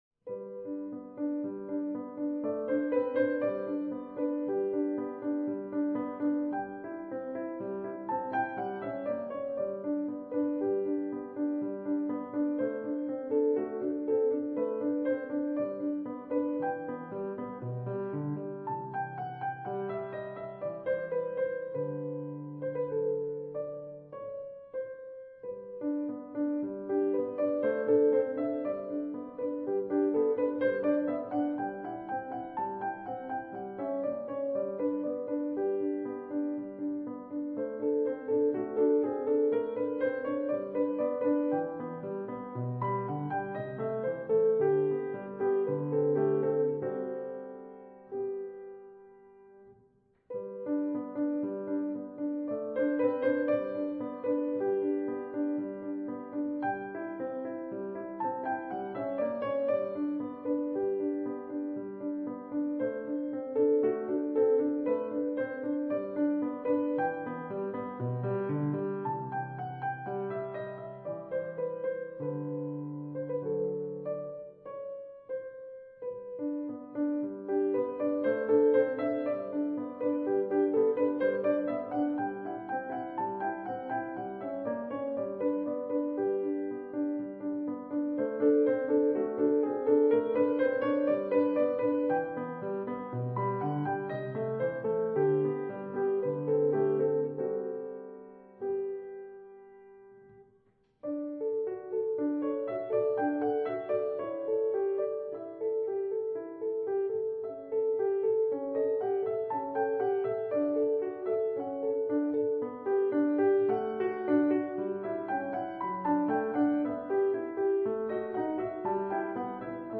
classical_56k.mp3